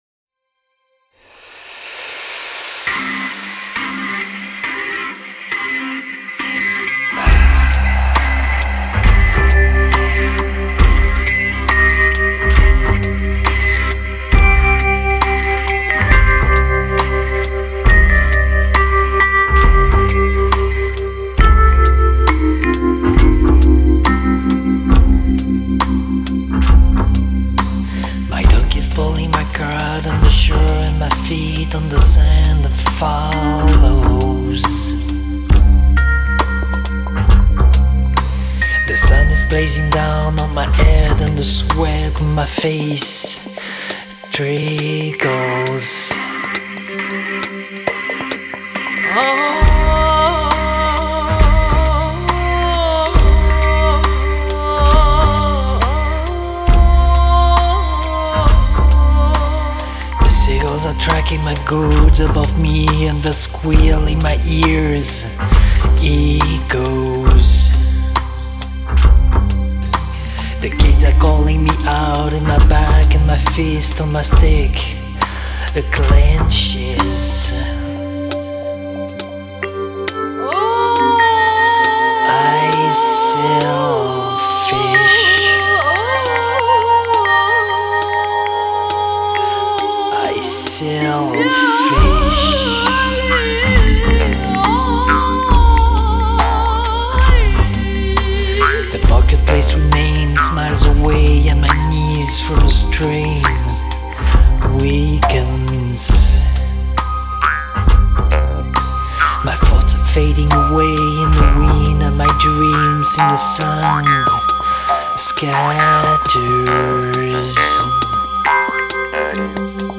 vocals, guitar, keybords, guimbarde, wok, programming
Vocals